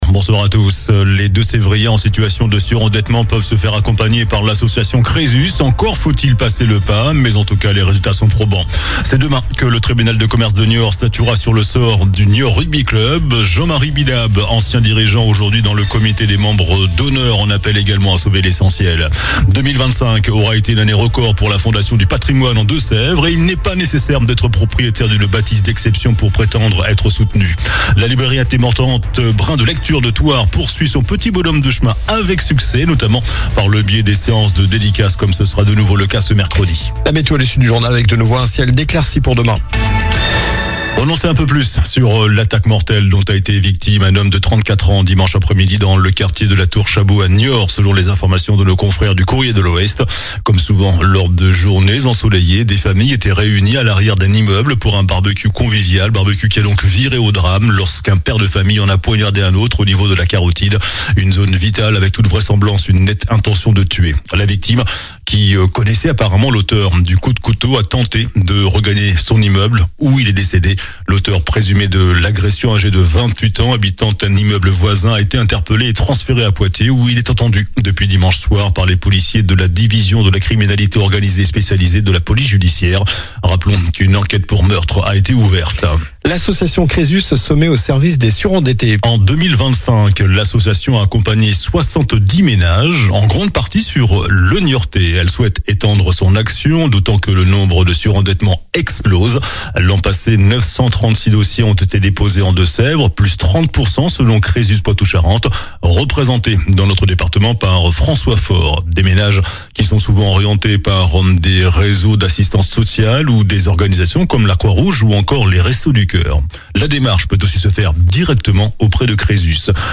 JOURNAL DU LUNDI 09 MARS ( MIDI )